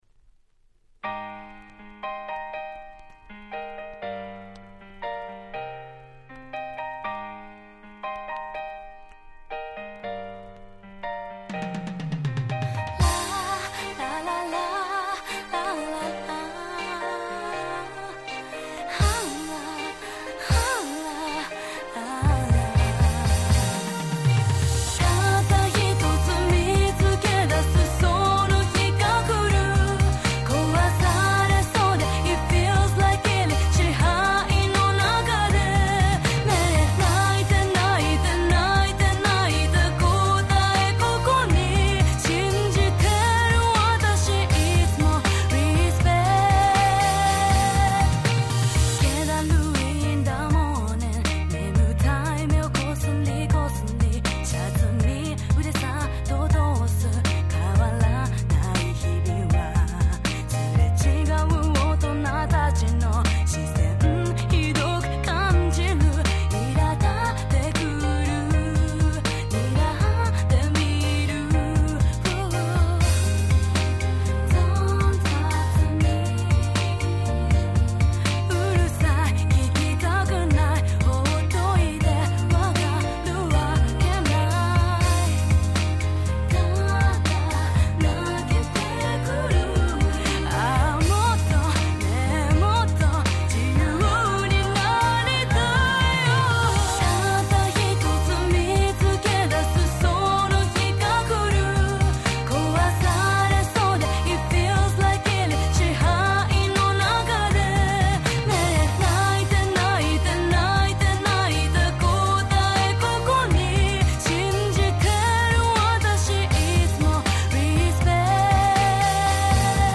06' Big Hit Japanese R&B !!